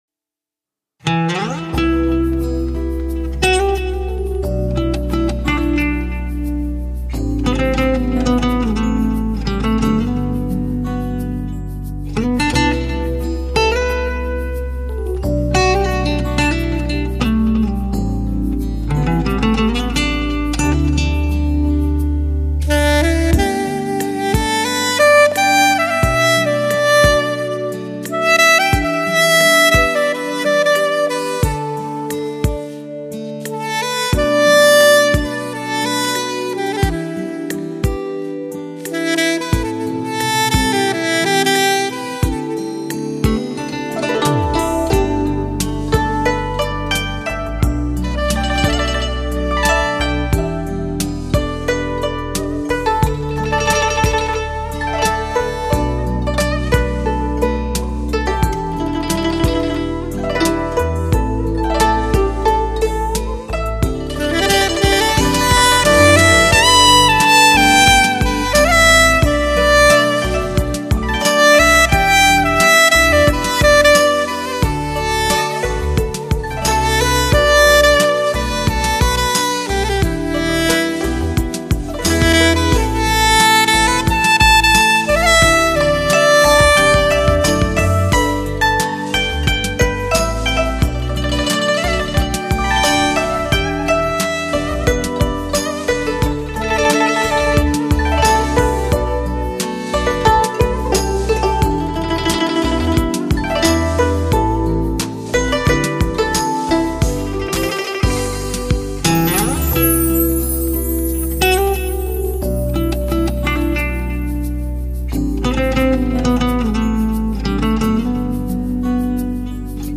唱片类型：轻音乐
专辑语种：纯音乐
由萨克斯、古筝描绘出的爱情百味，聆听属于你的爱情，属于你的